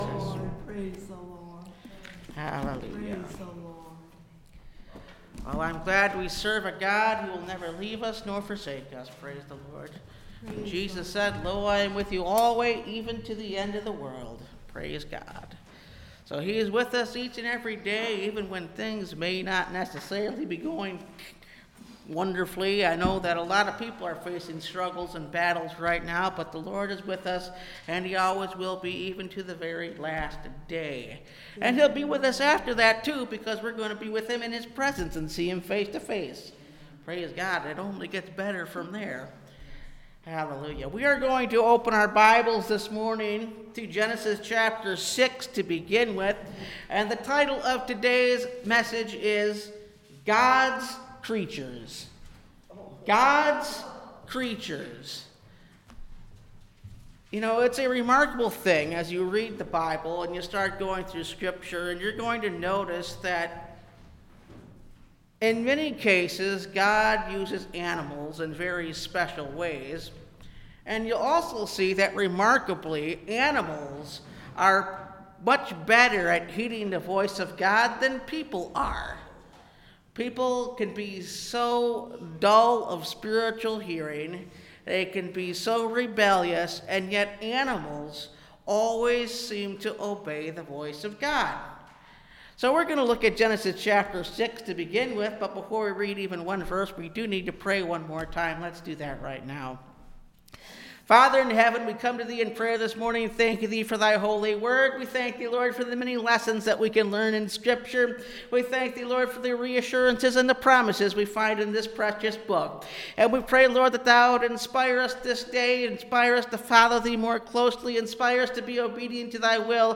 God’s Creatures (Message Audio) – Last Trumpet Ministries – Truth Tabernacle – Sermon Library